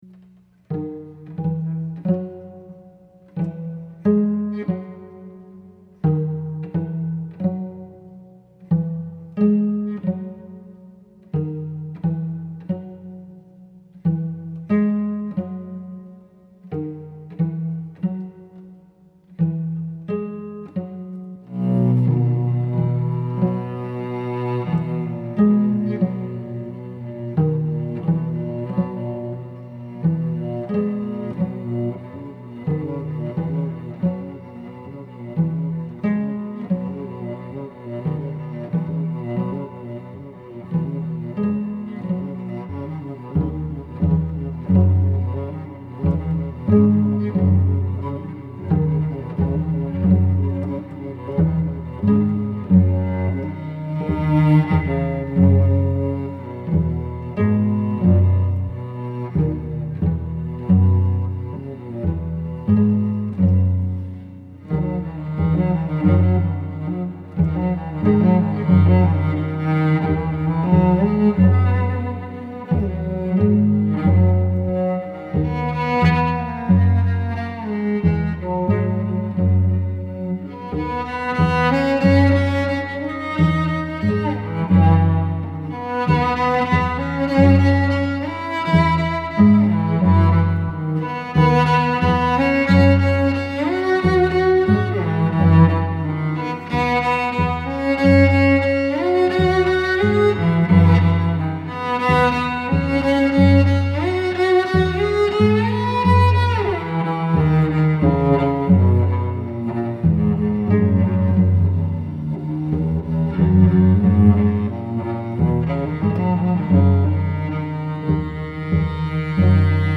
Tag: cello